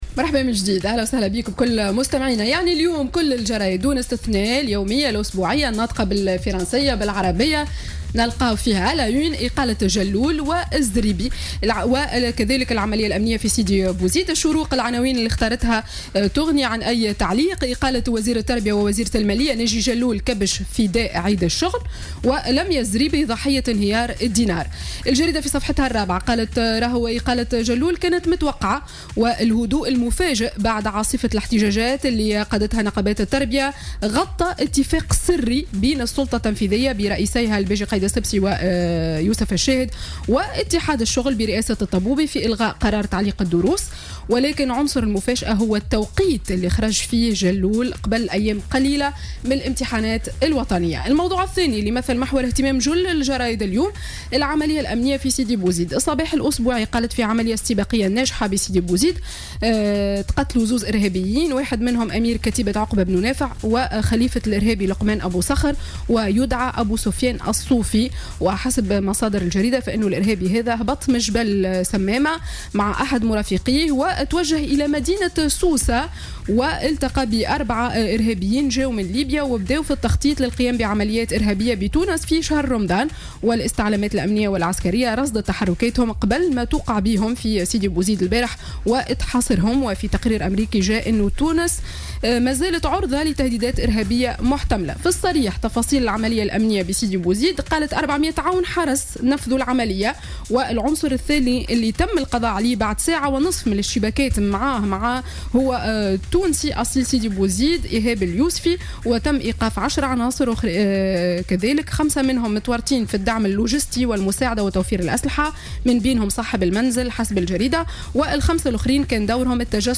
Revue de presse du lundi 1er mai 2017